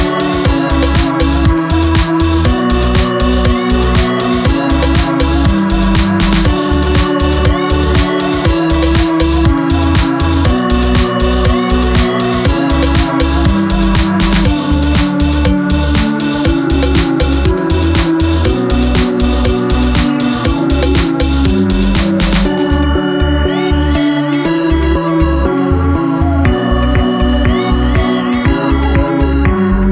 Жанр: Электроника / Русские